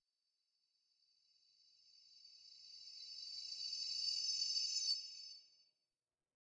Minecraft Version Minecraft Version latest Latest Release | Latest Snapshot latest / assets / minecraft / sounds / ambient / nether / warped_forest / enish2.ogg Compare With Compare With Latest Release | Latest Snapshot